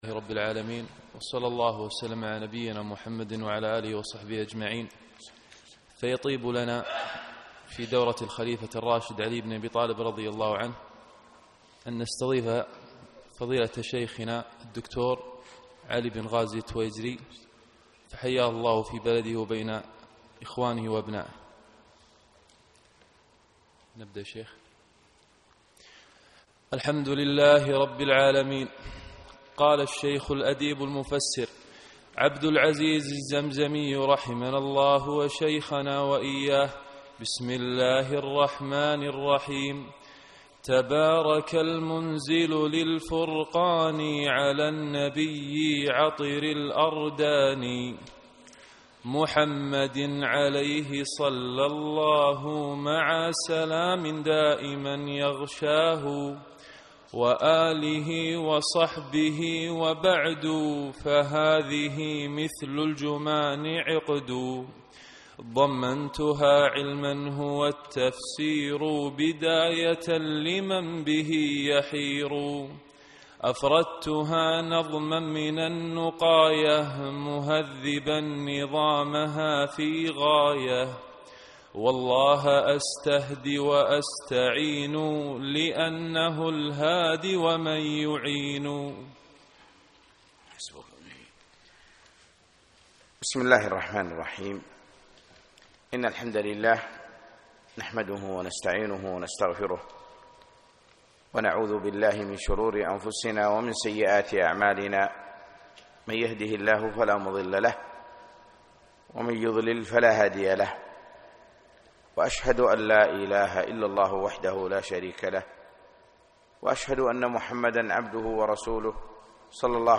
الدرس الأول : من بداية المنظومة إلى البيت 22